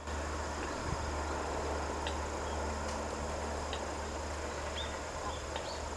他に観察舎前のガマの茂みからは得体のしれない声が聞けた。
短くティッ・ティッ・ティッと聞こえる・・・